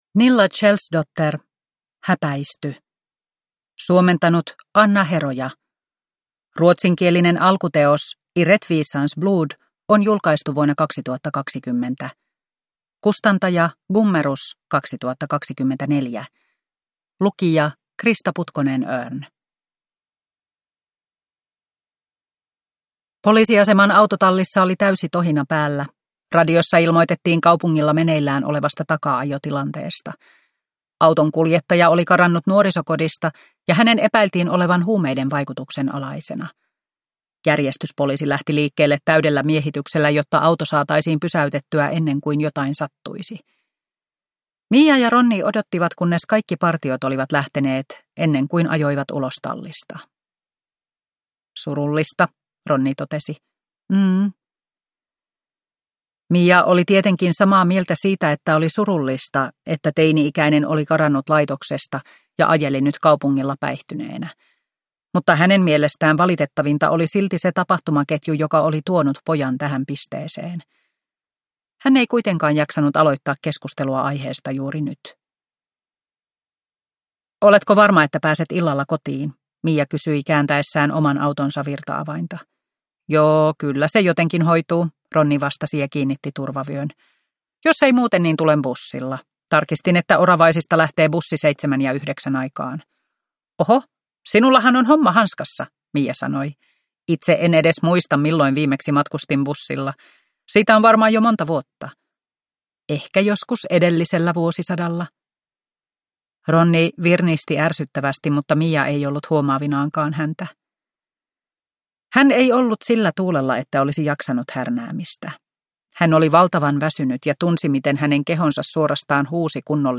Mija Wadö tutkii 3 (ljudbok) av Nilla Kjellsdotter